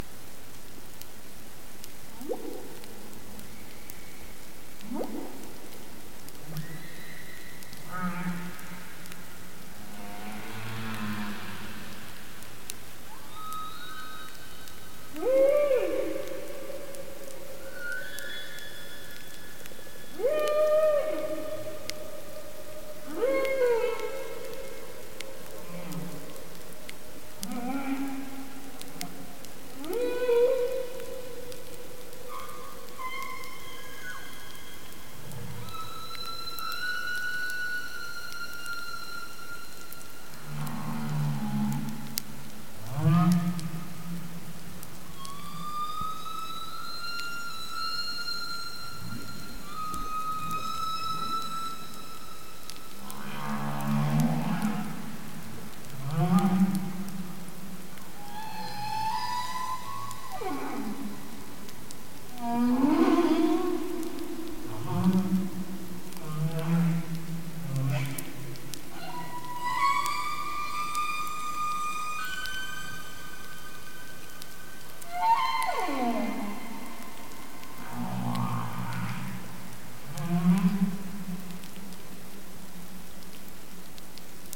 Протягом двох років команда використовувала мережу з 17 гідрофонів біля узбережжя Канади, щоб записувати пісні самців під час літнього і осіннього періодів.
Пісні включали короткі “фрази”, які з часом перетворювалися на чітко організовані “теми”, що часто повторювалися.
Такі пісні є складною послідовністю звуків, що можуть виконуватися як поодинці, так і групами, і розповсюджуються на значні відстані в океані.
a-humpback-whale-song-fragment-from-early-in-the-season.mp3